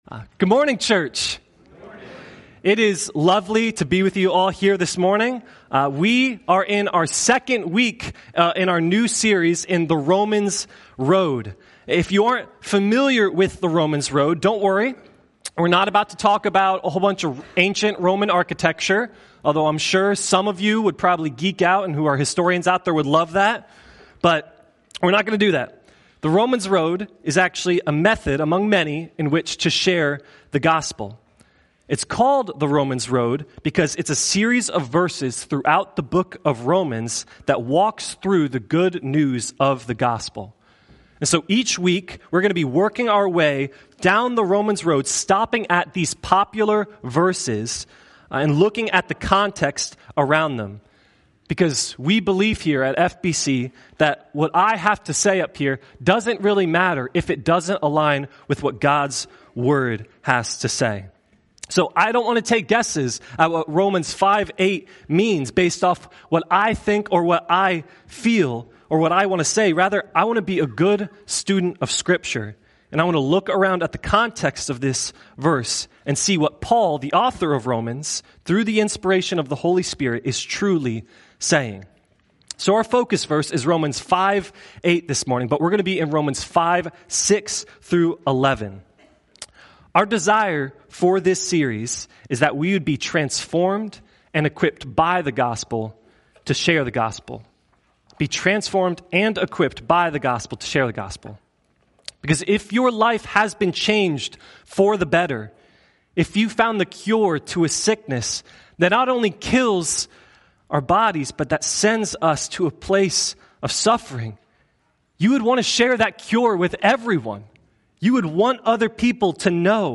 Sunday Morning The Romans Road